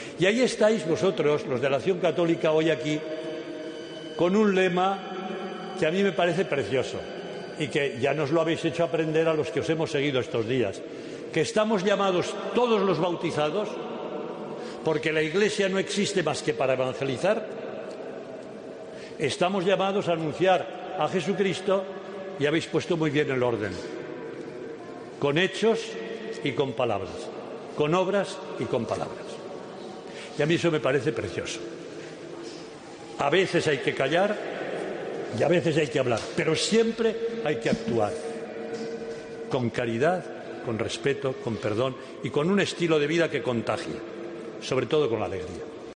Omella, en la Misa del Encuentro de Laicos de Acción Católica: "Vuestra alegría brota del encuentro con Jesús"
Este domingo ha tenido lugar el acto de clausura y la eucaristía de envío en la Iglesia de Santa María del Mar de Barcelona
El presidente de la CEE, que como muchos de los participantes, sufrió el intenso calor dentro de la catedral, decidió dejar de un lado la homilía que tenía preparado y dejó algunas palabras improvisadas: “Voy a hacer un pequeño resumen de la homilía y os atiendo mejor”.